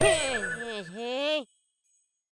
Npc Catstun Sound Effect
npc-catstun.mp3